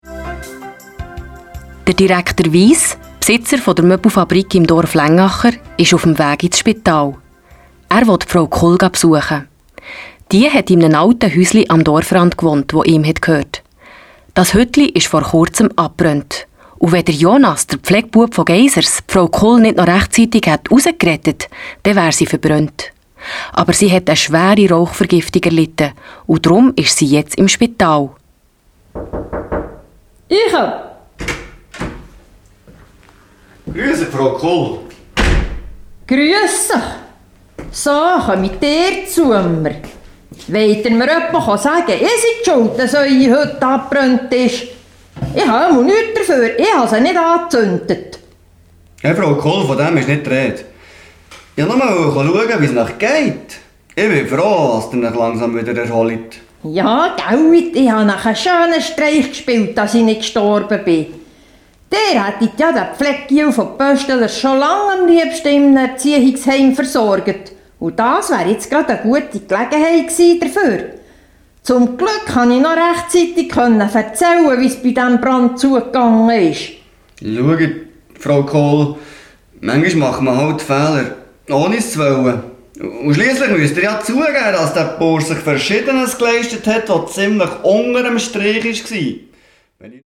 Hörspiel ab 8 Jahren